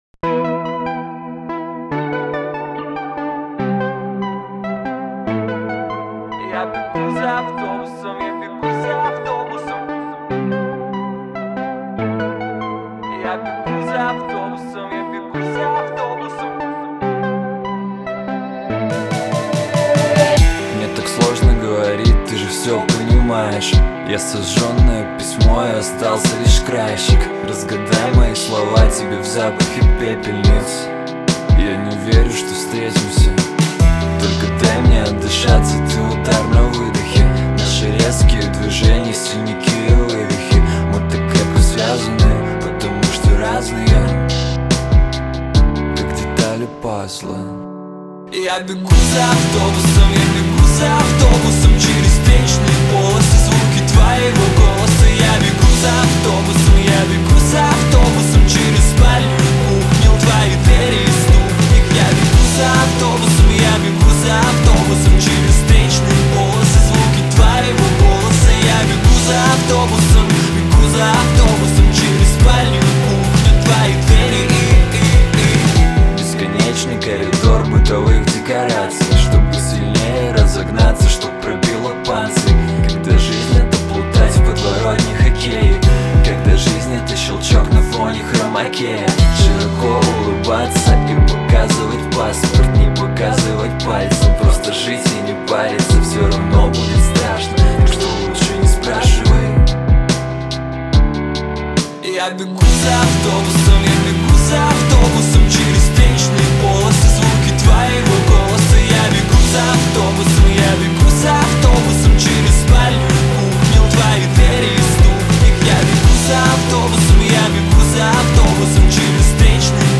мастерски сочетает мелодичность с ритмичным звучанием